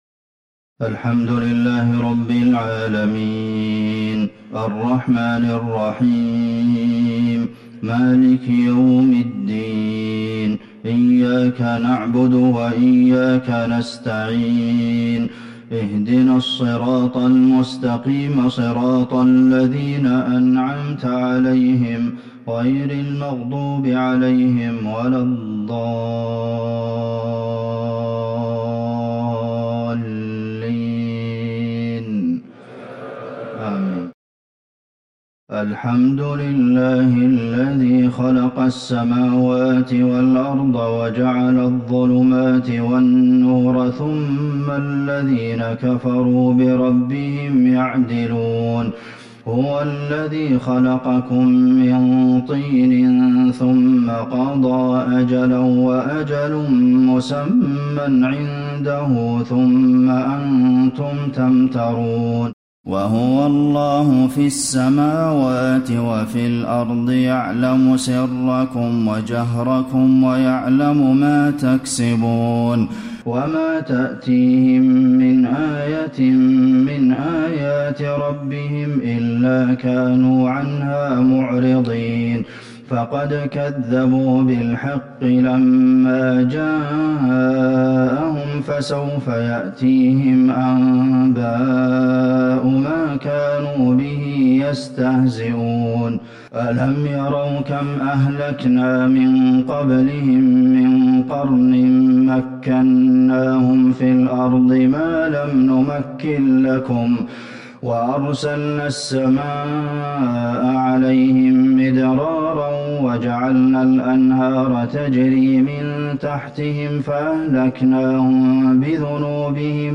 صلاة الفجر للشيخ عبدالمحسن القاسم 7 جمادي الآخر 1441 هـ
تِلَاوَات الْحَرَمَيْن .